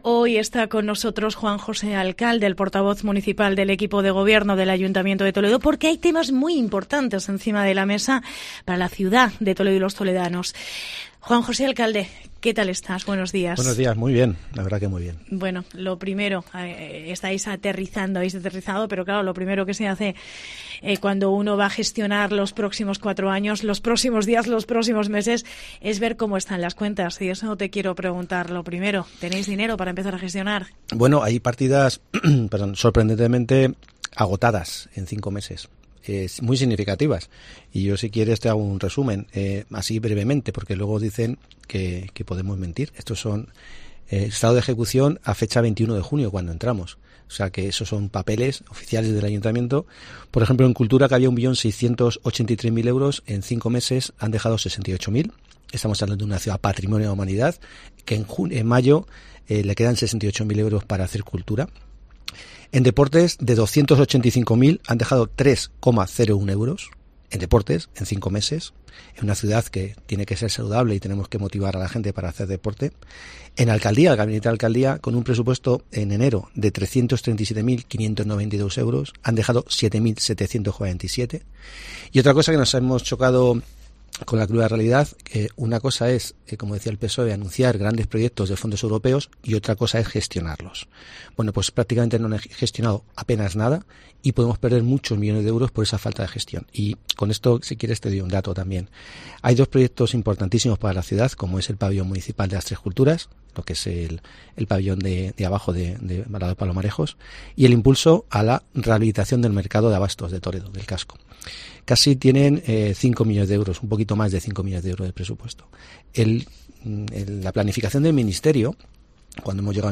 ENTREVISTA
Juan José Alcalde ha visitado la redacción de COPE Toledo y ha tratado temas como el estado de las cuentas, el PERIM del Hotel Beatriz y la polémica sobre el precio del bonobus